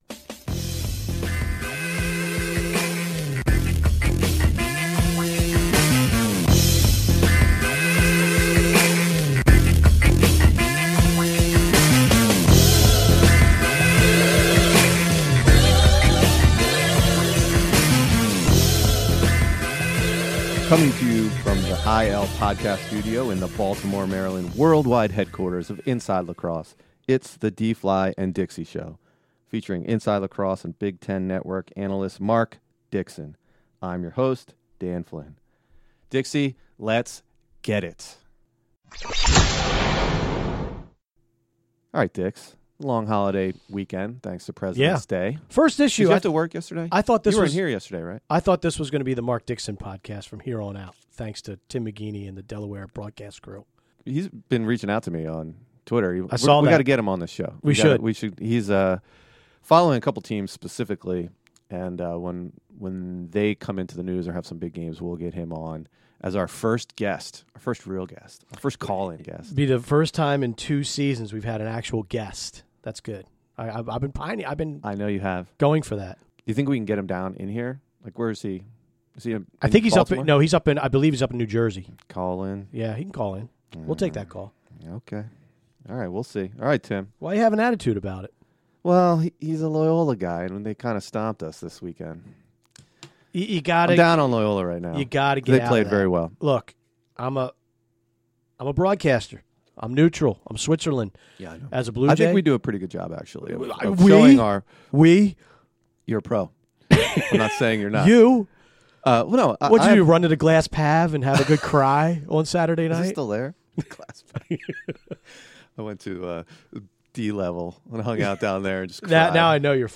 They also take calls from listeners. In this week's Black Panther-inspired “Give and Go,” they talk about their favorite superhero films.